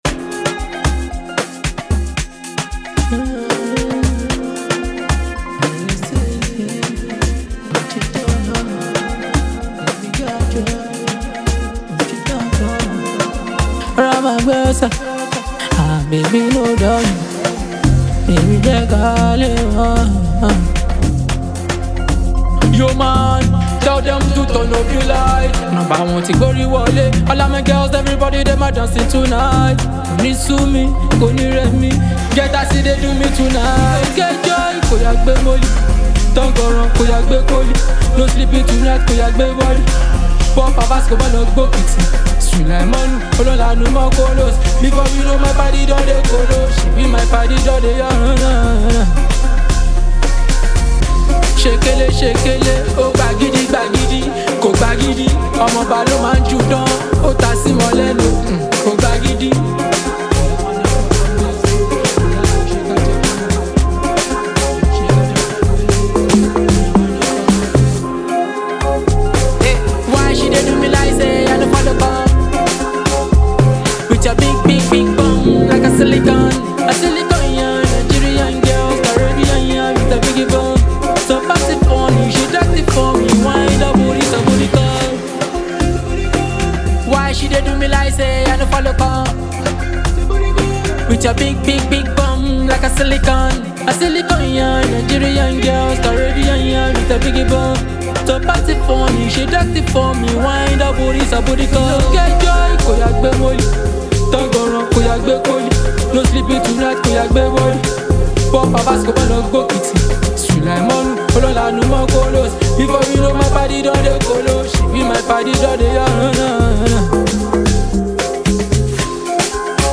street and dancehall Afrobeat song
thanks to its playful lyrics and dance-ready rhythm.